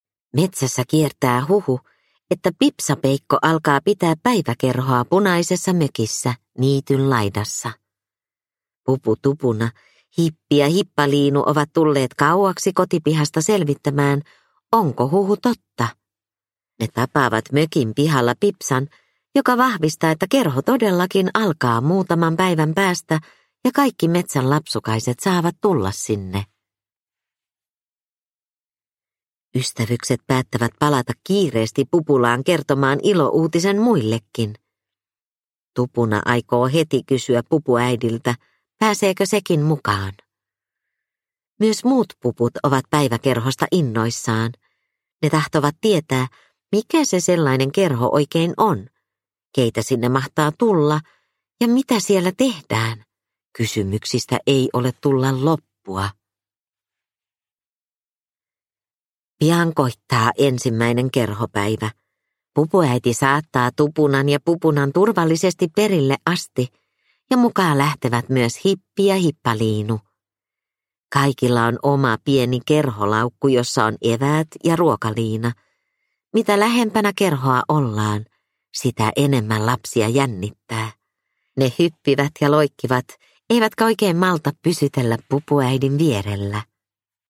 Pupu Tupuna päiväkerhossa – Ljudbok – Laddas ner